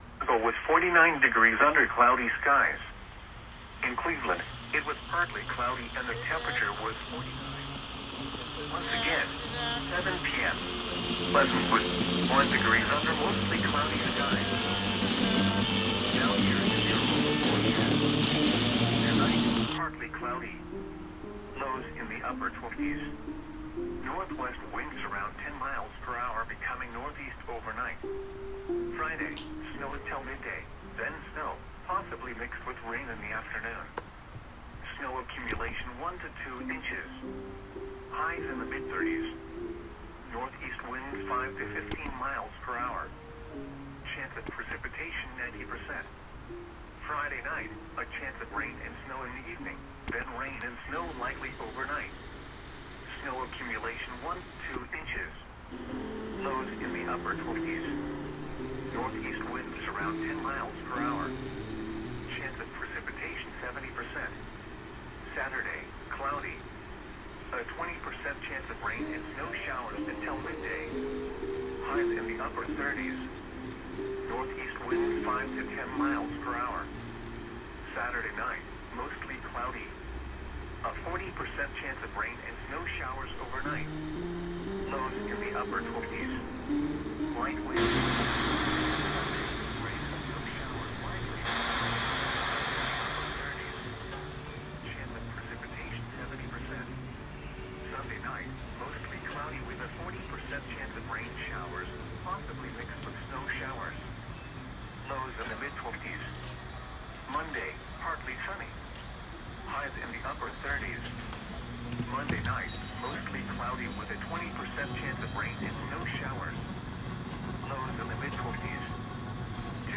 Starting with a series of deep listening exercises, participants then took a series of recordings of radio, exploring intuitive making, response improvisation, and “signal sweeps”.
The workshop culminated in a creative commons of participant’s recordings, available for all to ‘remix’.